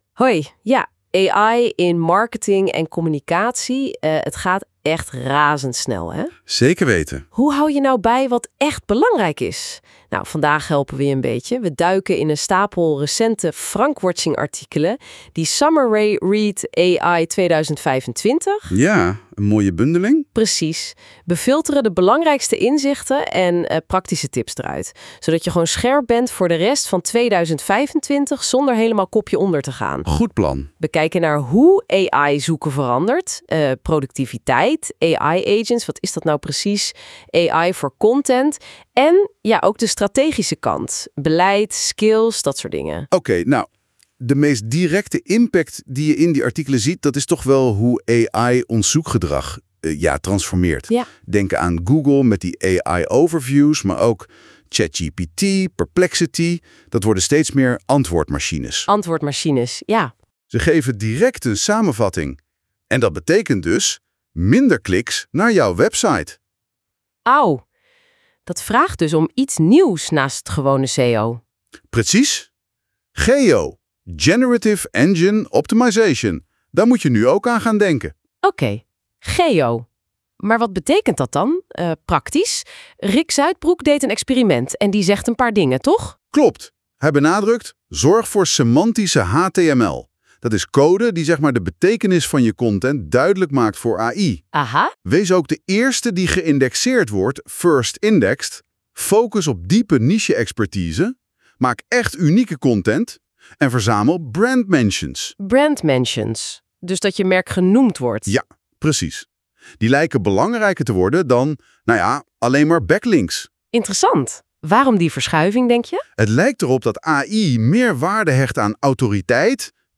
Laat je door 2 AI-hosts in 8 minuten bijpraten over deze summerread, gegenereerd door NotebookLM.